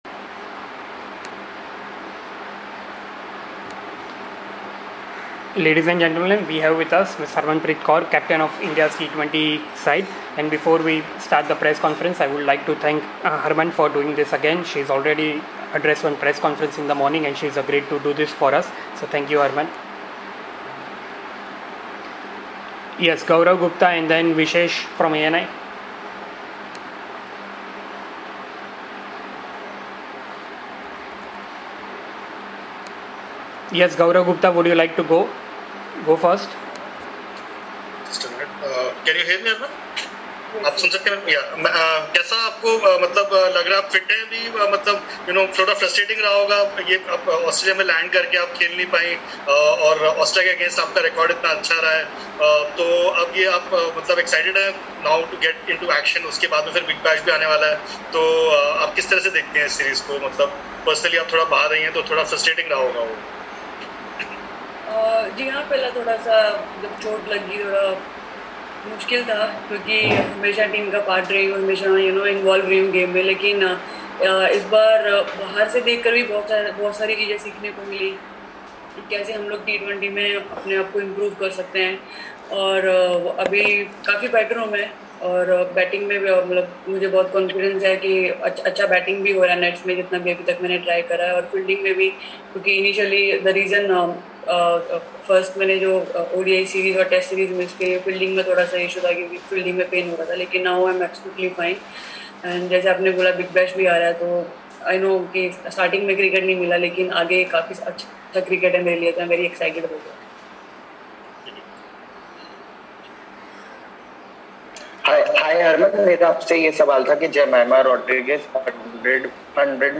Harmanpreet Kaur, Captain, Indian Cricket Team (T20I) addressed a virtual press conference ahead of the first T20I against Australia to be played on Thursday at Metricon Stadium, Carrara.